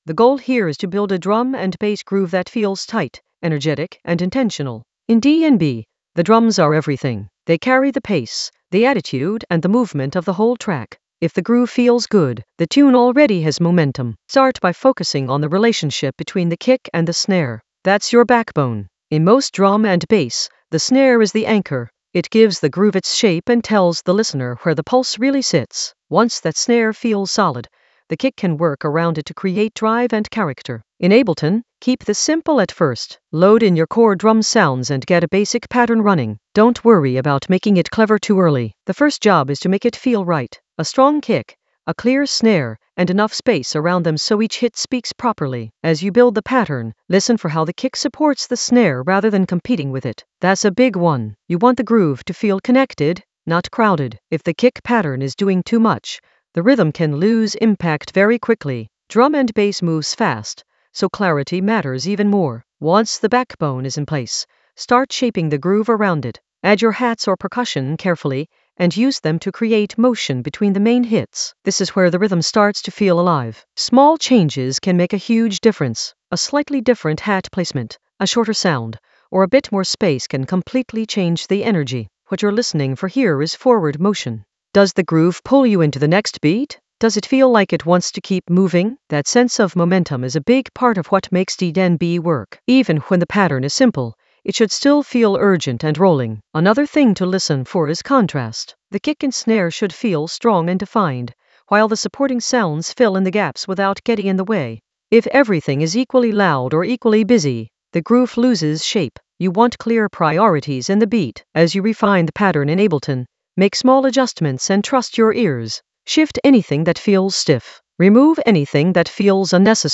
An AI-generated beginner Ableton lesson focused on Ramos Atmos in the FX area of drum and bass production.
Narrated lesson audio
The voice track includes the tutorial plus extra teacher commentary.